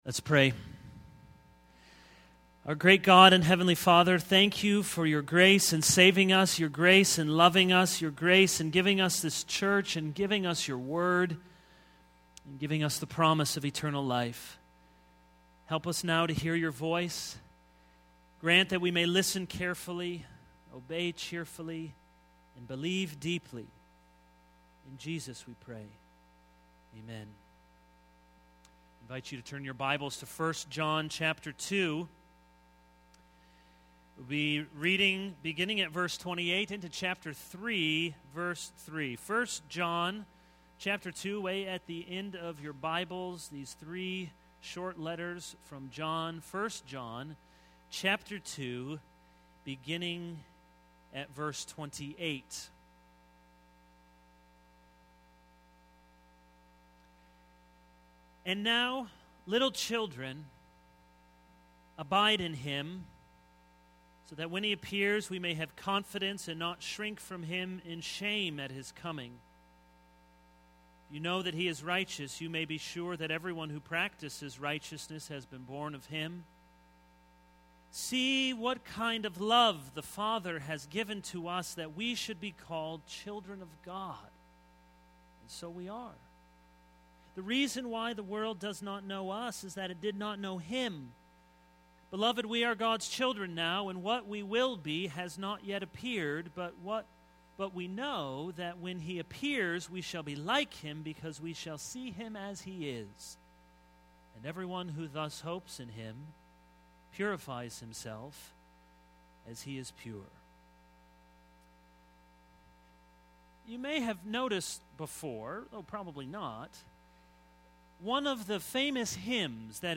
This is a sermon on 1 John 2:28-3:3.